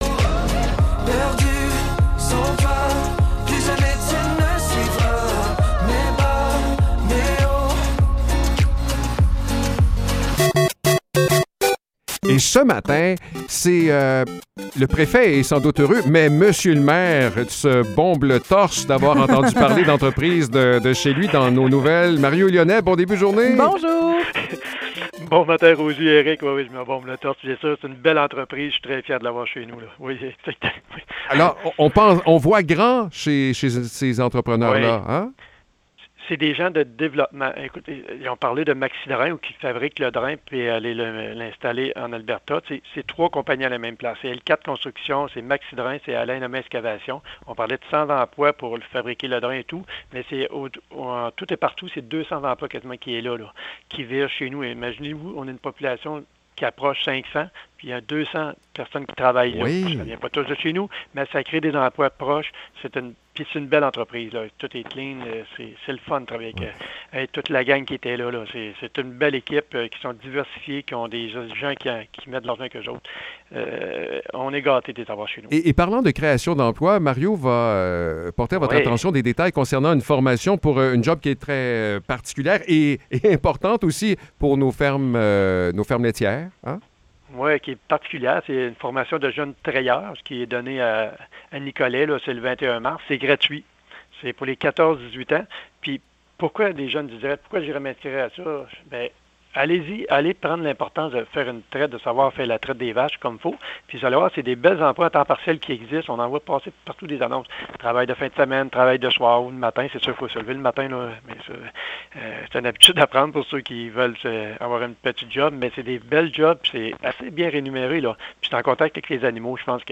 Échange avec Mario Lyonnais